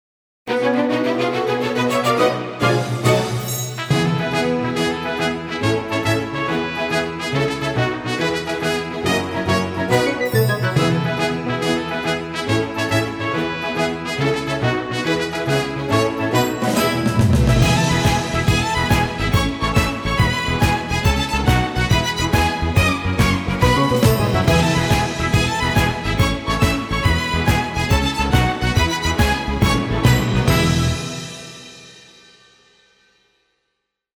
PT8 шёлкает и шуршит при задержке в 64 семпла